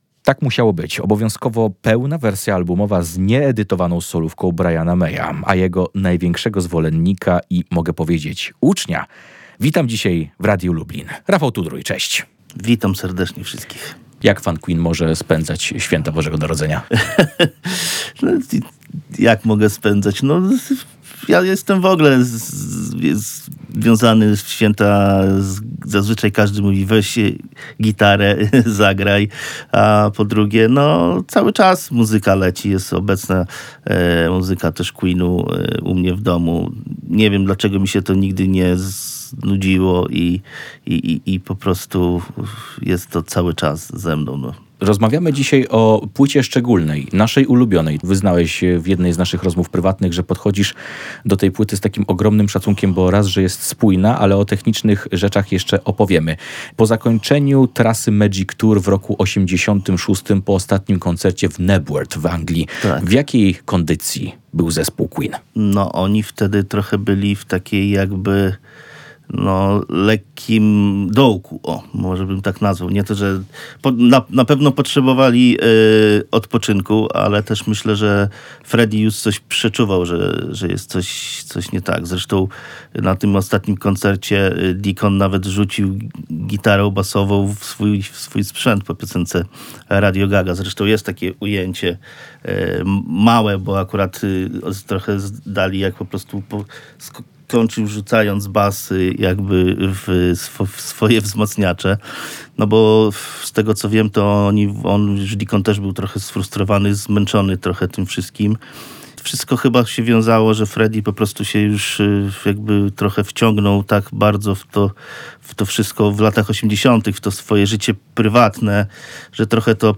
30 lat płyty "Made in Heaven" Queen [POSŁUCHAJ ROZMOWY]